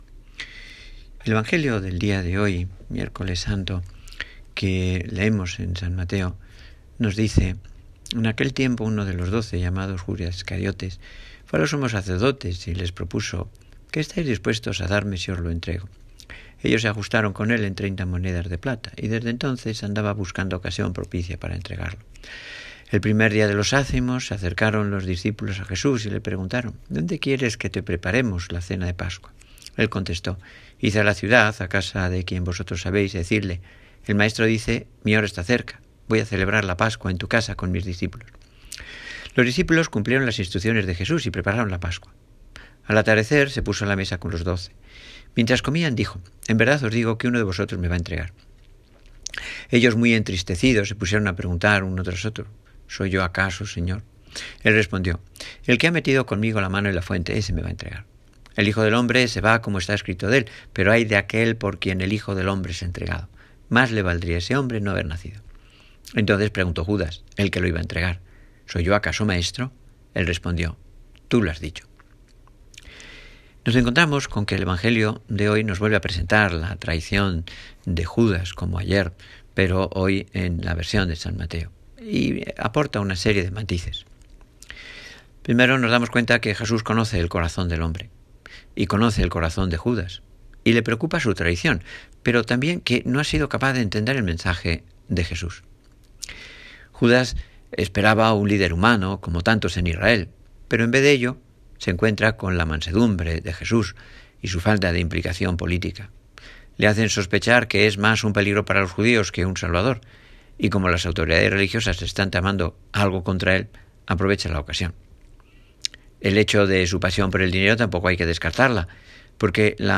Aquí tenéis, como siempre en archivo de voz y en texto, la meditación de nuestro Párroco para el Miércoles Santo.